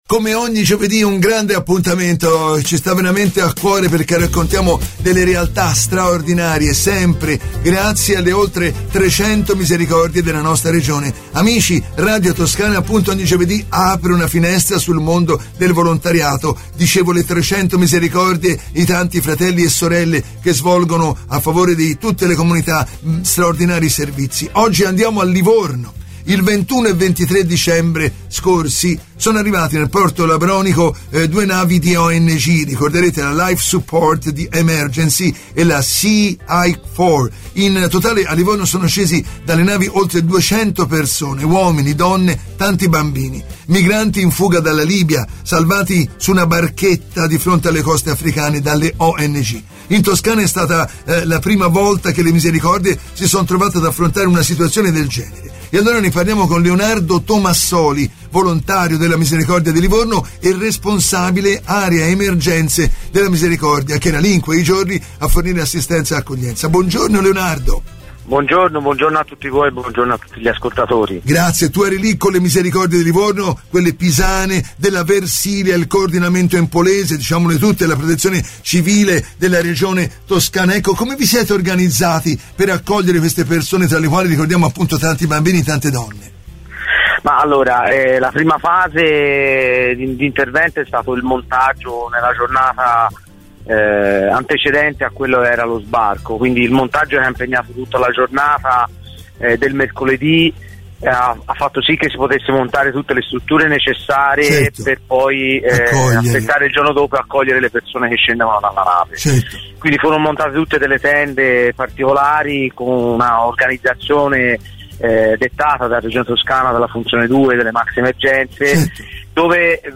Una testimonianza toccante.